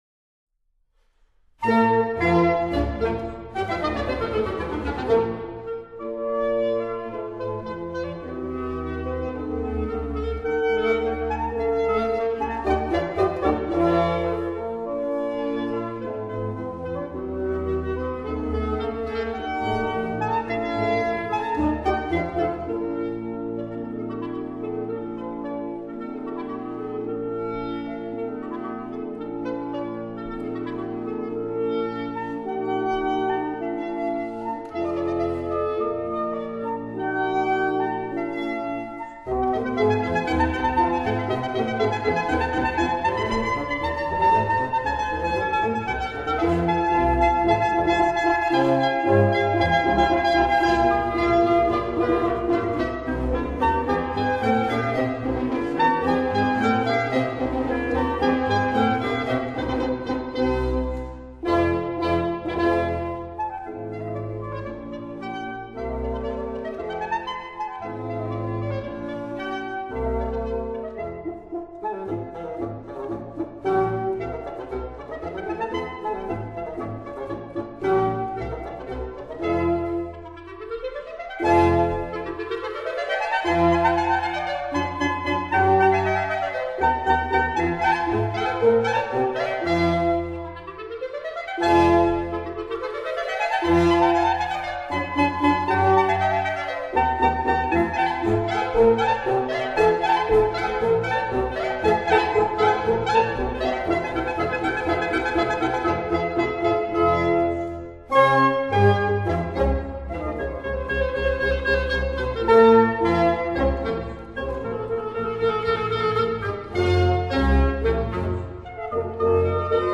clarinet
natural horn
bassoon
double bass